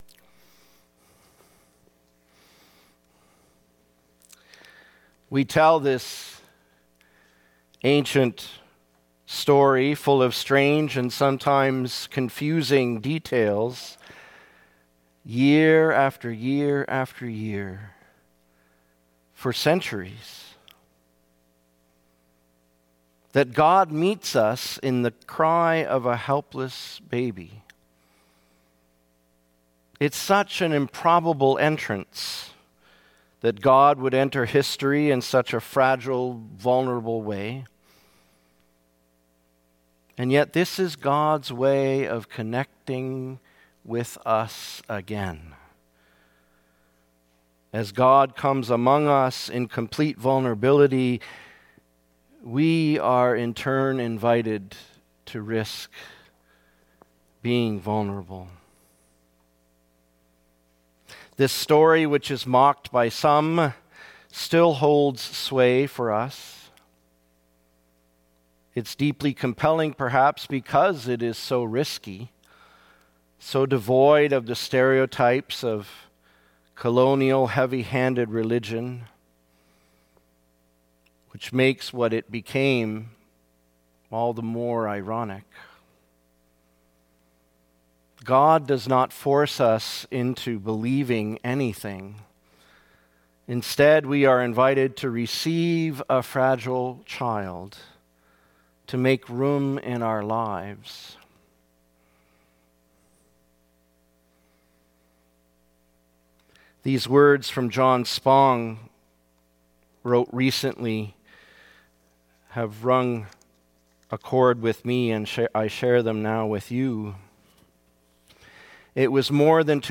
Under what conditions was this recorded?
December 24, 2022 Reflection - Christmas Eve Candlelight Service